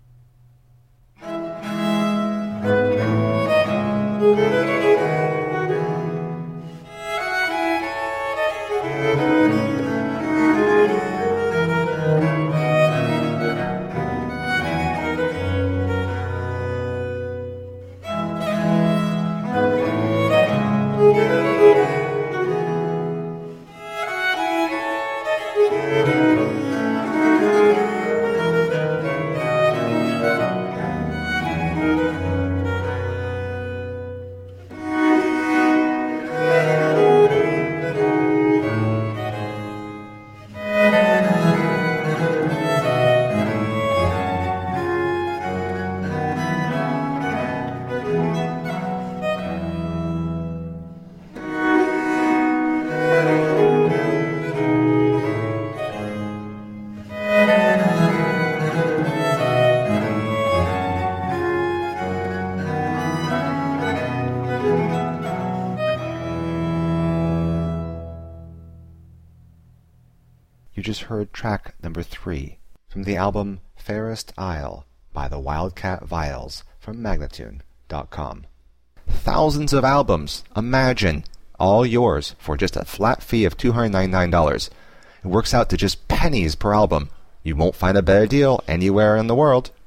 Viola da gamba girls gone wild!!.